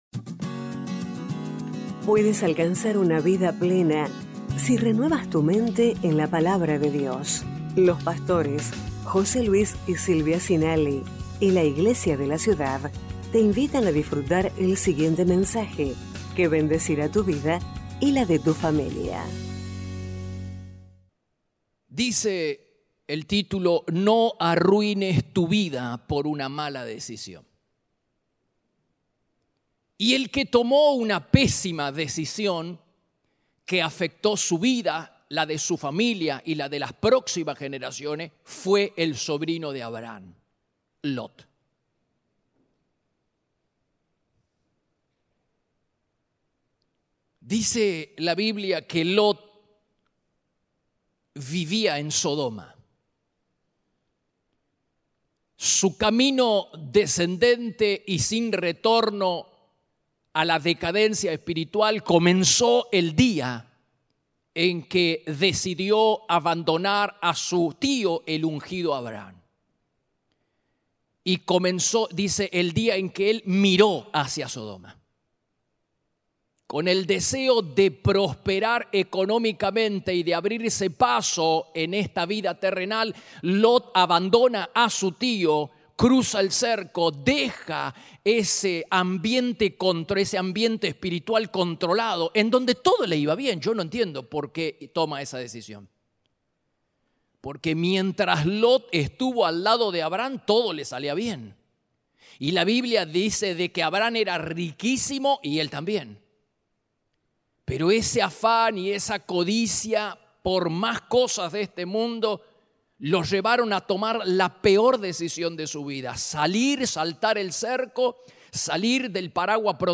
Iglesia de la Ciudad - Mensajes / No arruines tu vida por una mala decisión 25/6/2023 #1254